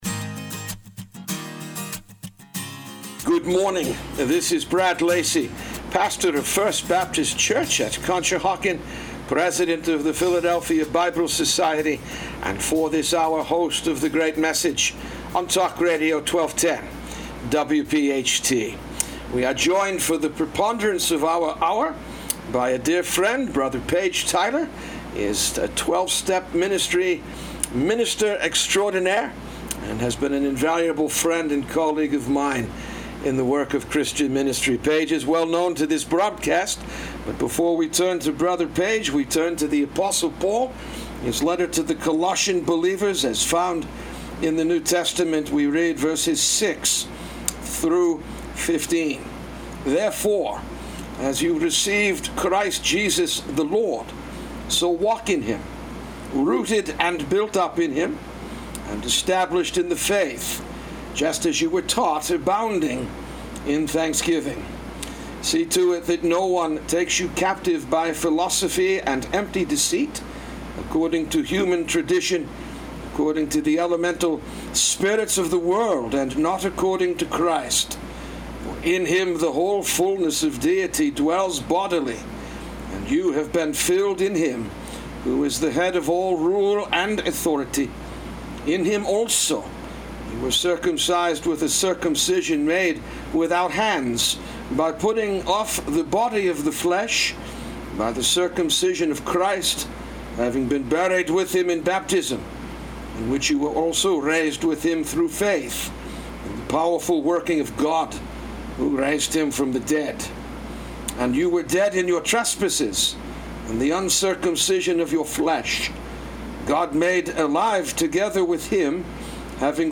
The Great Message A Conversation